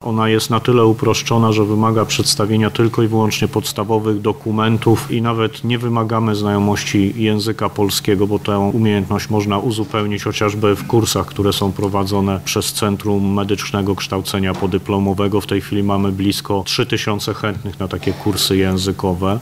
– Obecnie stara się o to około 400 osób, ale uproszczoną procedurę przyjmowania medyków z zagranicy do pracy mamy od roku – mówi minister zdrowia Adam Niedzielski.